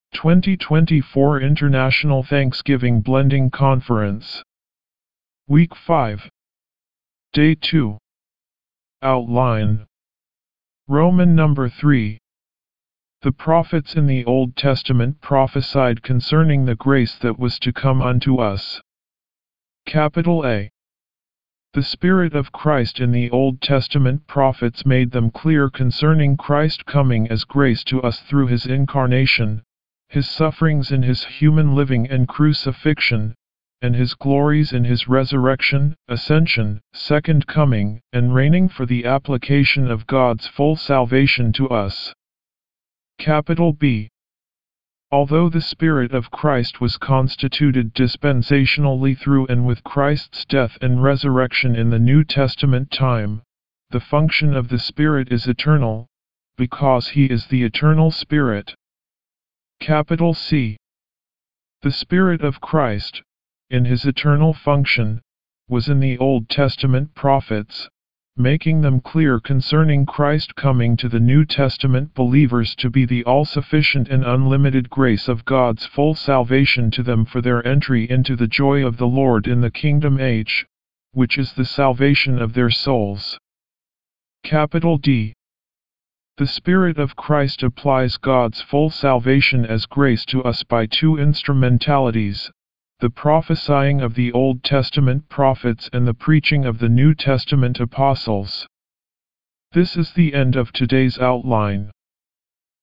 D2 English Rcite：